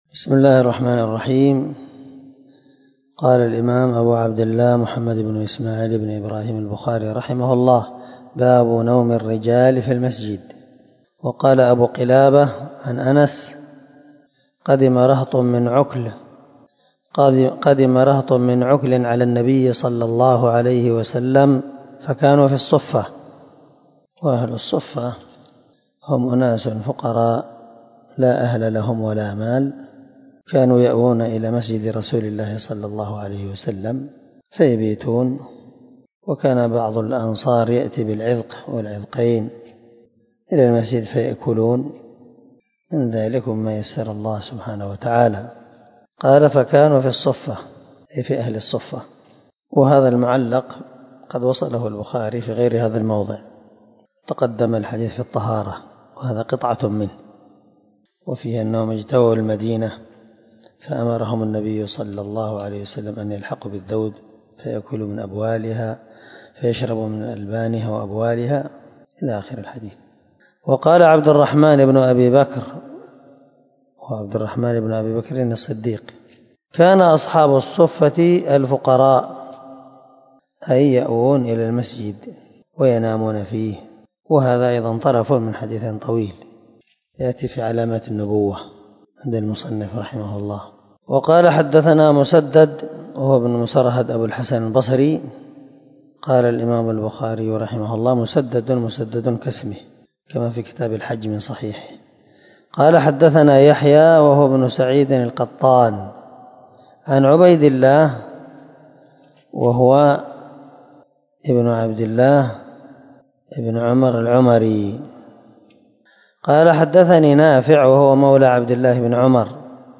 329الدرس 62 من شرح كتاب الصلاة حديث رقم ( 440 – 441 ) من صحيح البخاري